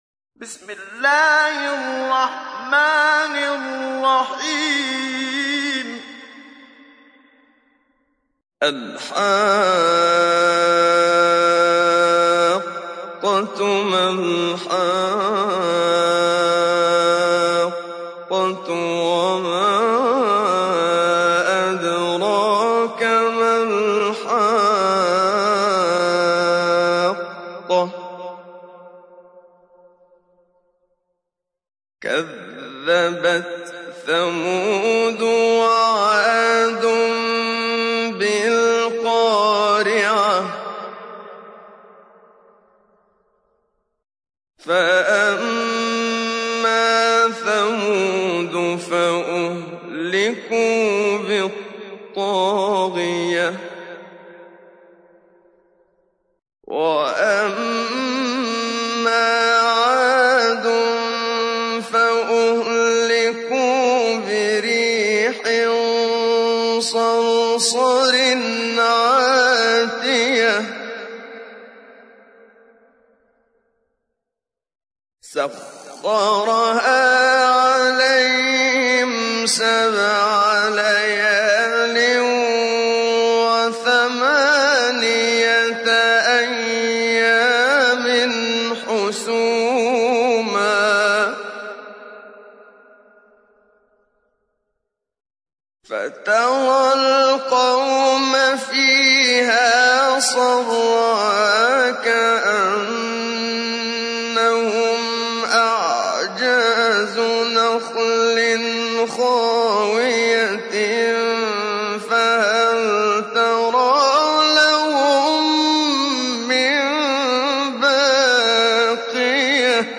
تحميل : 69. سورة الحاقة / القارئ محمد صديق المنشاوي / القرآن الكريم / موقع يا حسين